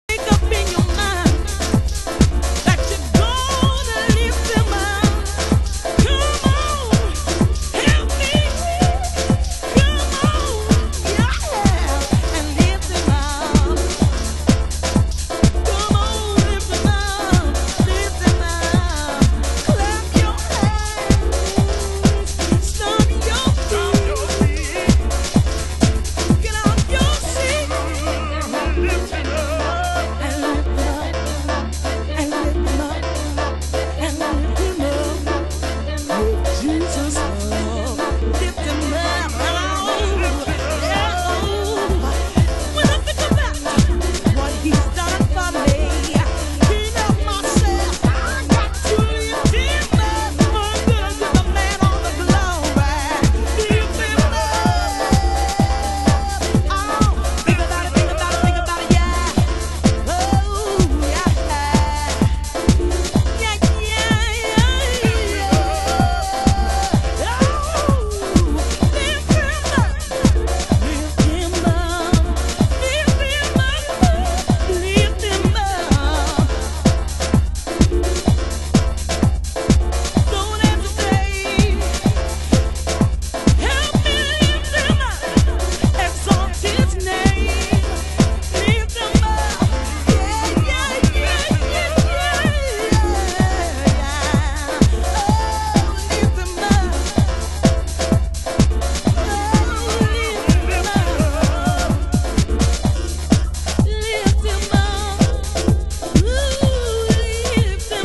HOUSE MUSIC
盤質：少しチリパチノイズ有